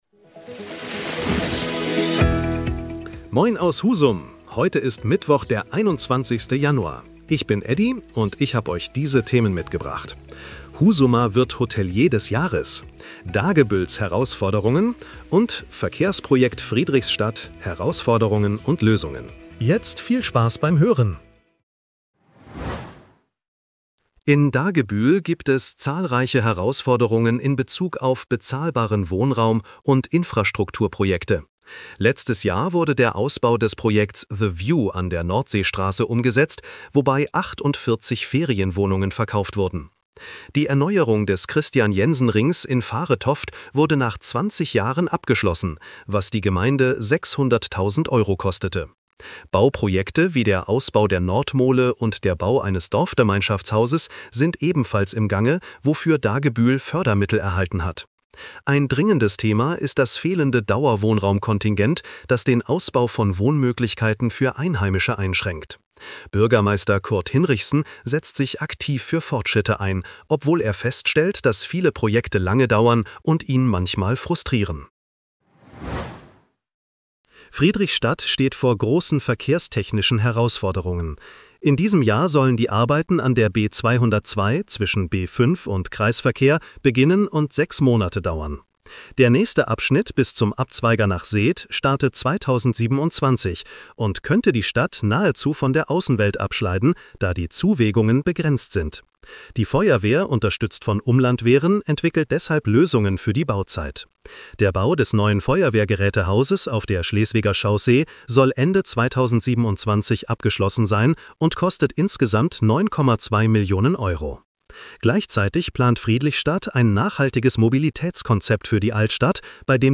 Deine täglichen Nachrichten
Nachrichten